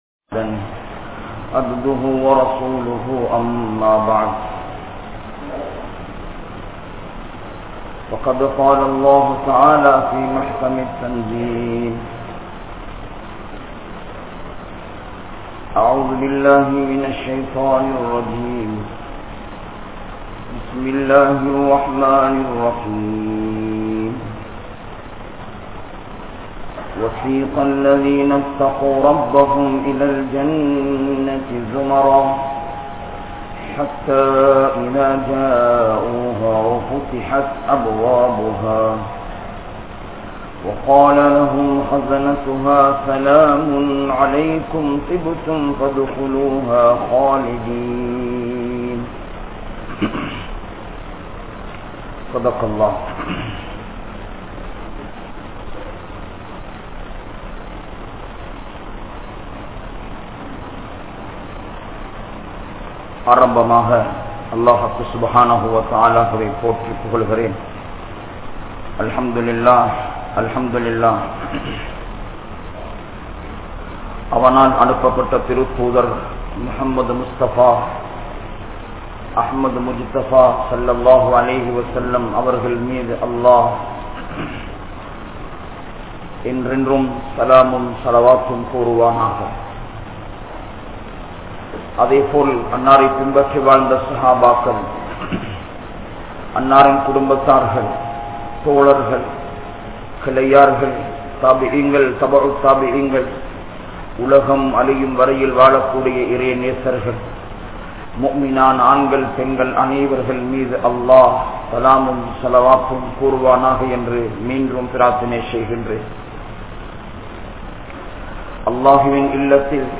Allah`vin Anpu | Audio Bayans | All Ceylon Muslim Youth Community | Addalaichenai